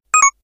powerUp6.ogg